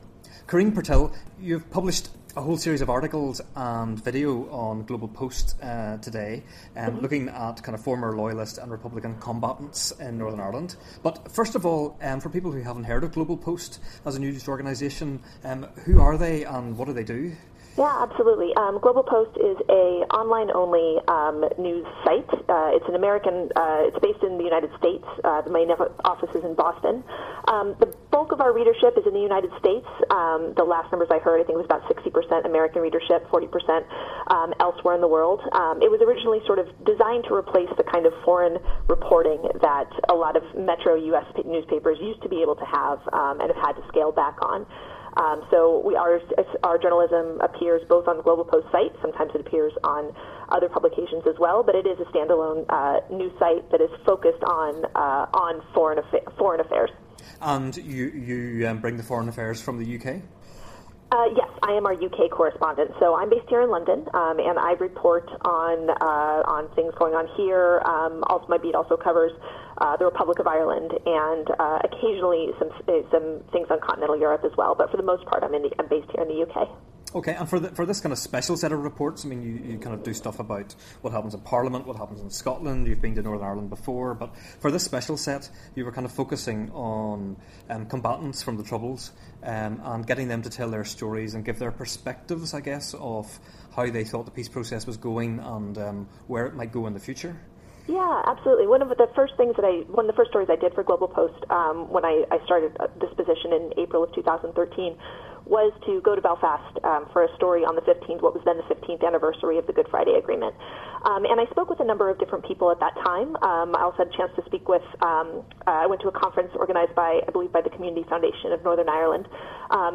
Interviewing